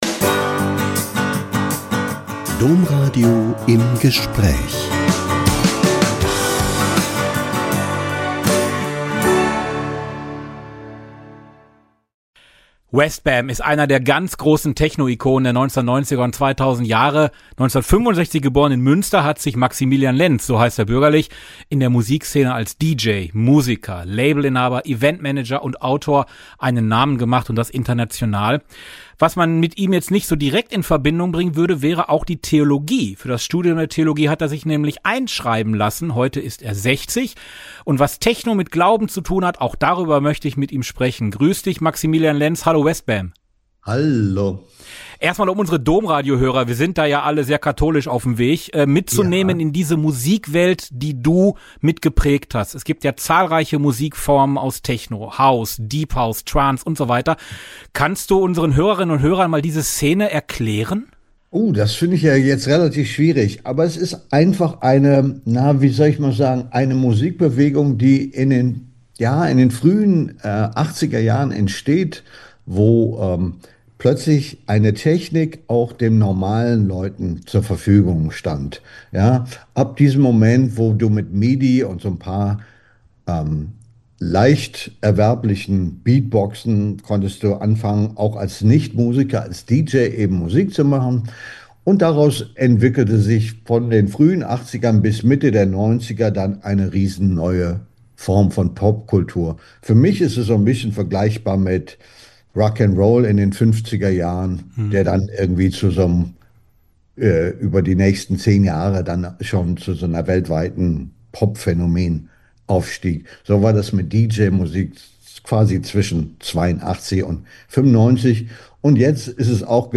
Woran der DJ Westbam wirklich glaubt - Ein Interview mit Westbam (bürgerlich Maximilian Lenz, DJ, Musiker, Labelinhaber, Eventmanager und Autor) ~ Im Gespräch Podcast